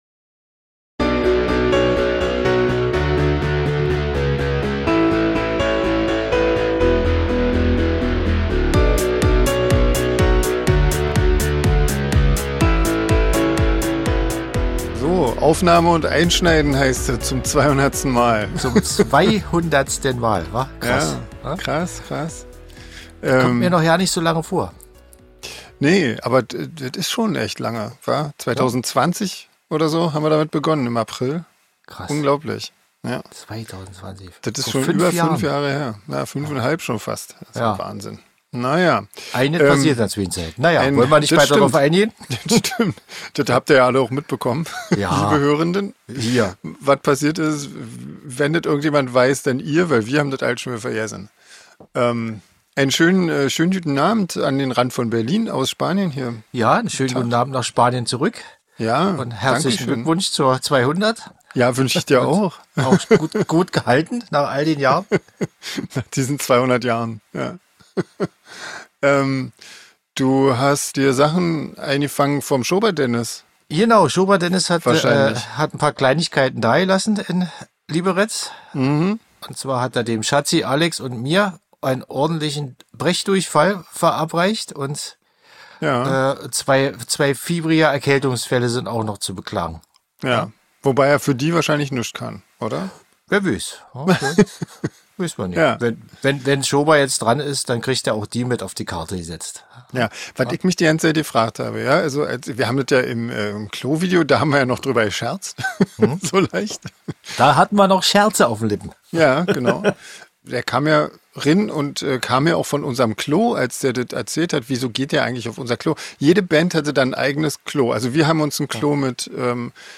Und ganz am Ende gibt es noch die Outtakes von der Hörspiel-Aufnahme.